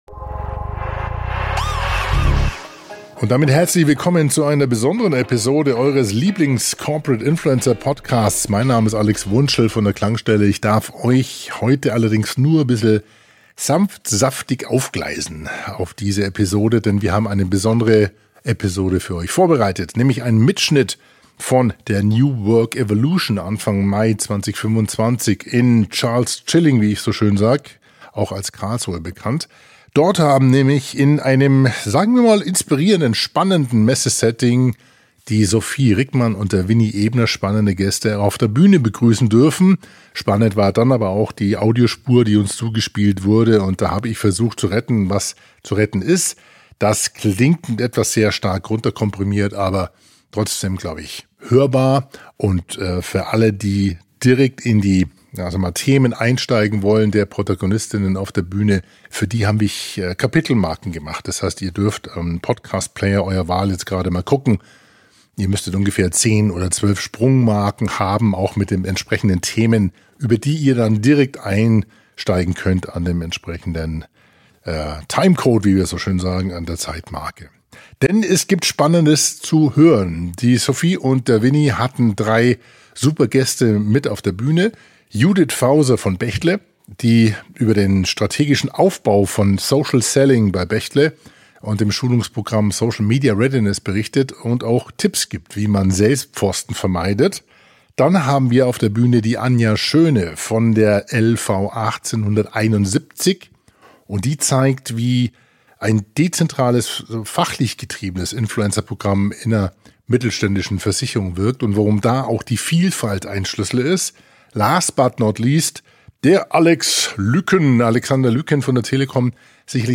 Corporate Influencing wirkt! Aber nur mit Haltung, Herz und Hirn - Live von der NWE ~ Corporate Influencer Podcast - Menschen.